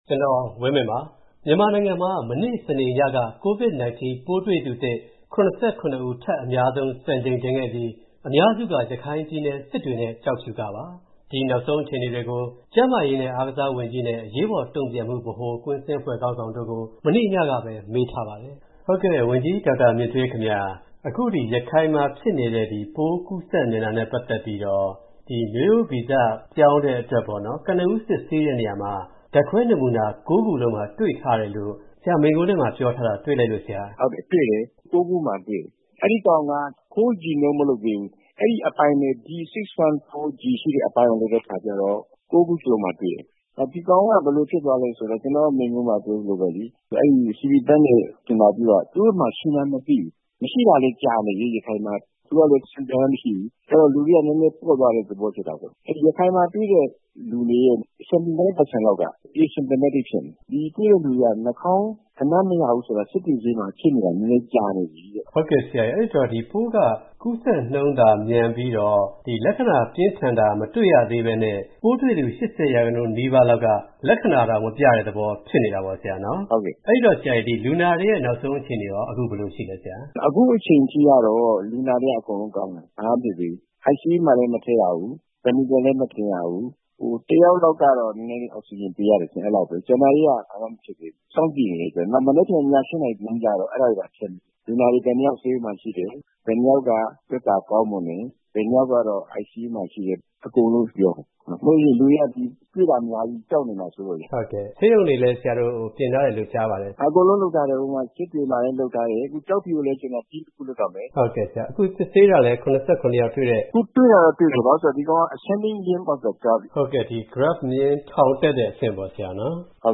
ရခိုင် COVID နောက်ဆုံးအခြေအနေ ကျန်းမာရေးဝန်ကြီး၊ အရေးပေါ် တုံ့ပြန်ကိုင်တွယ်မှု ခေါင်းဆောင်တို့နဲ့ မေးမြန်းခန်း